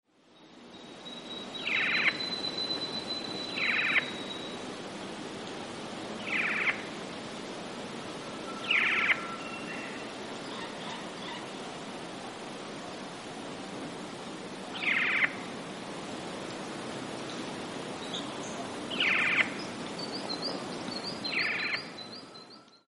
Black-Faced Cuckoo-Shrike - Coracina novaehollandiaen
Voice: musical rolling churring.
Call 1: churring calls
Black_f_Cuckooshr.mp3